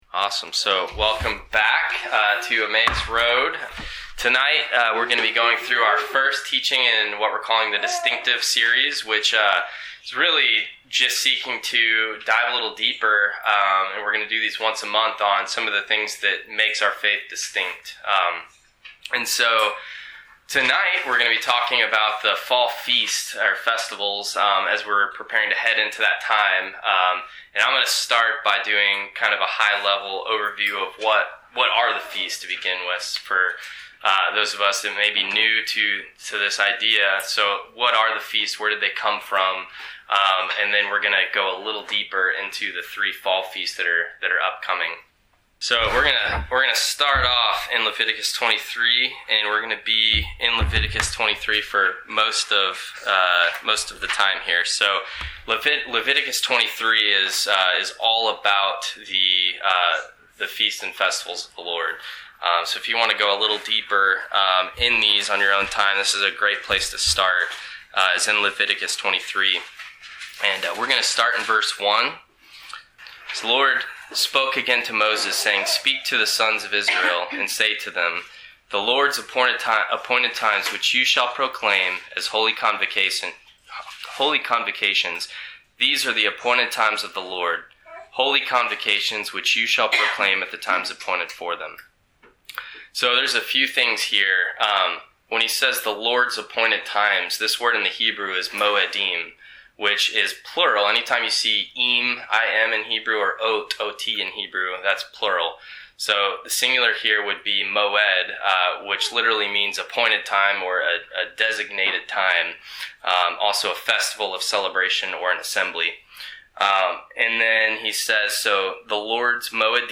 This teaching is part of our Distinctives Series and gives an overview of the Appointed Times, focusing on Rosh Hashanah, the Day of Atonement and the Festival of Sukkot (Tabernacles).